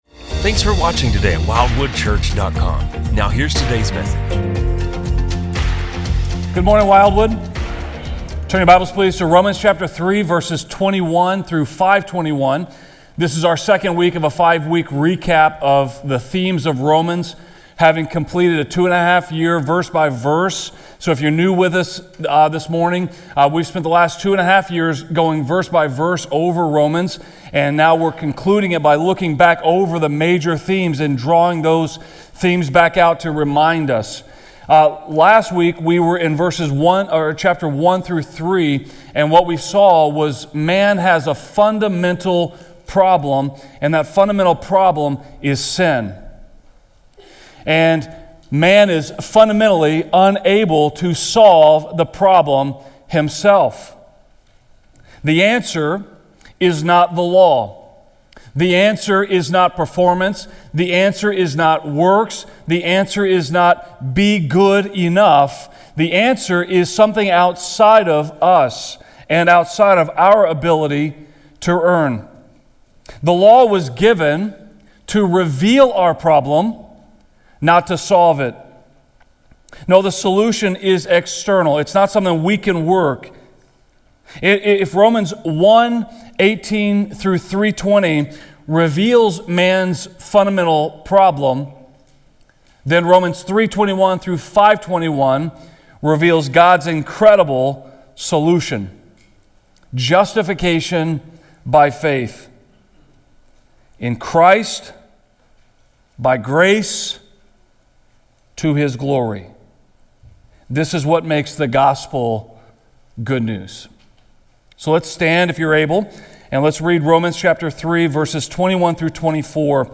This sermon revisits the heart of the Gospel: we are all sinners, unable to save ourselves—but God, in His grace, justifies us through faith in Jesus.